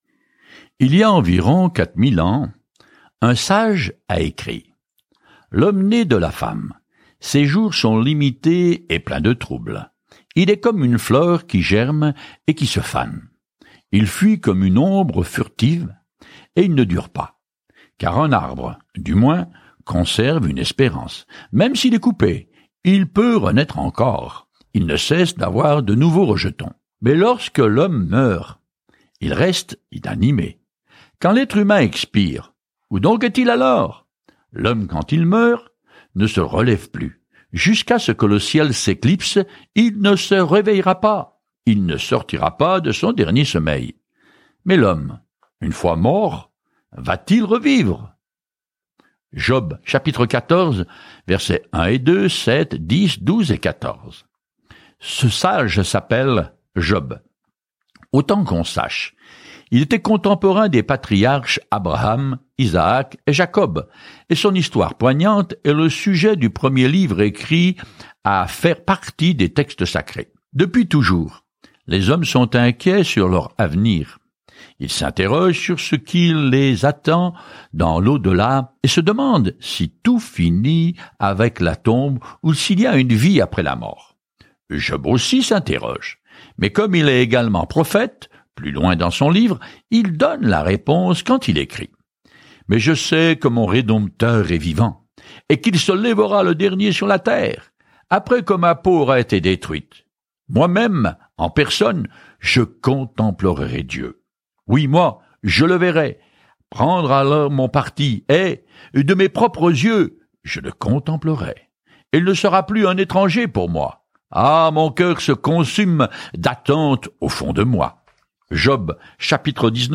Parcourez quotidiennement 1 Corinthiens en écoutant l’étude audio et en lisant des versets sélectionnés de la parole de Dieu.